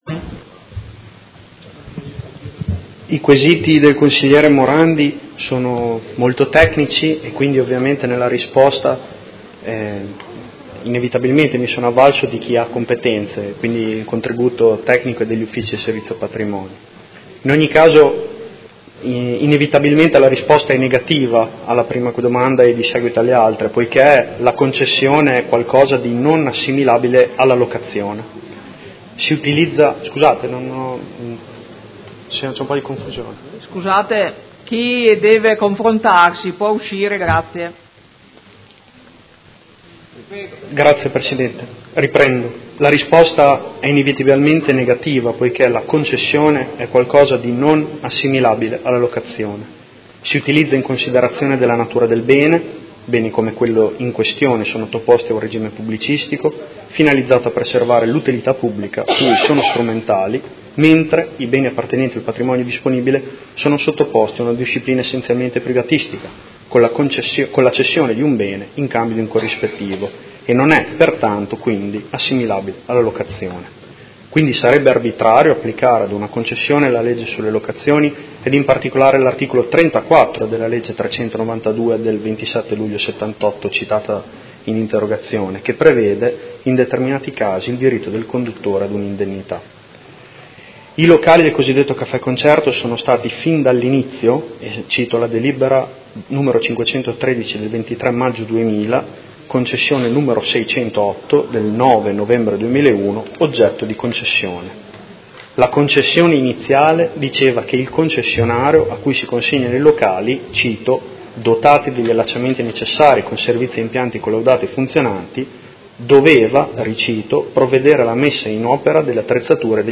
Seduta del 15/11/2018 Risponde. Interrogazione del Consigliere Morandi (FI) avente per oggetto: Avviso di gara per la concessione del “Caffè Concerto”, eventuale richiesta d’idennità per perdita avviamento e risarcimento per lavori eseguiti